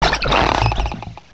sovereignx/sound/direct_sound_samples/cries/veluza.aif at master